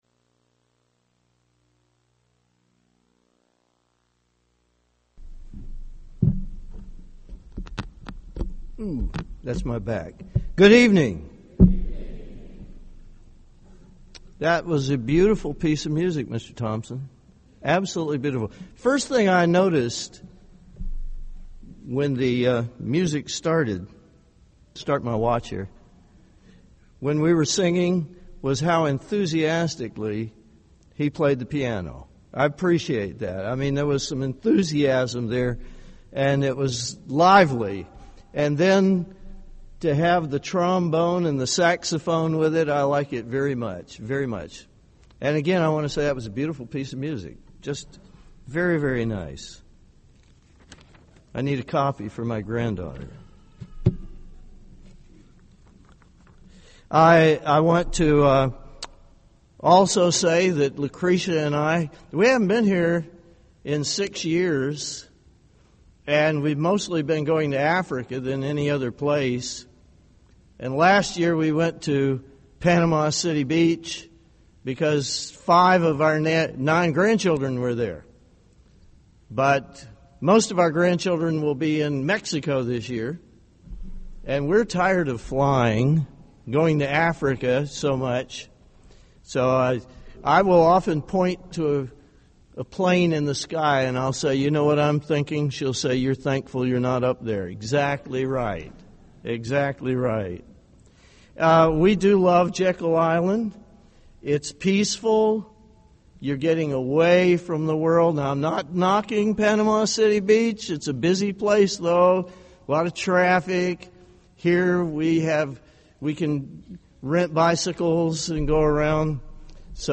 This sermon was given at the Jekyll Island, Georgia 2014 Feast site.